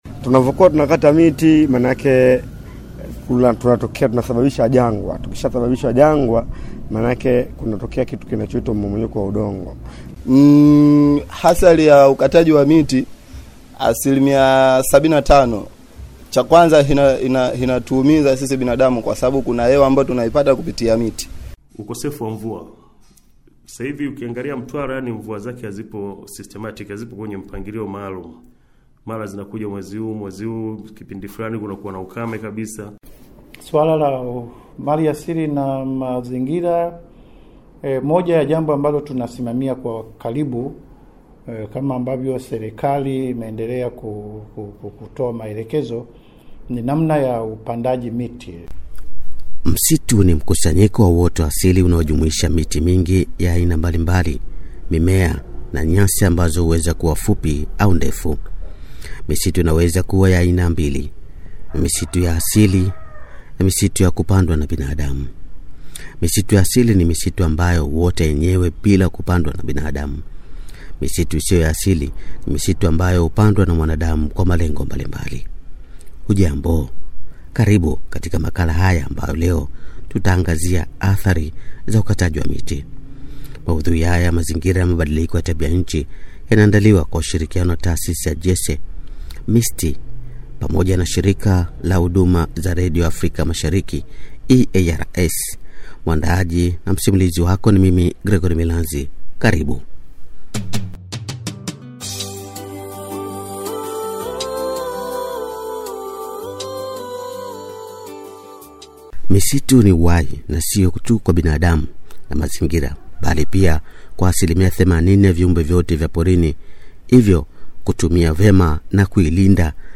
makala-ya-athari-za-ukataji-miti.mp3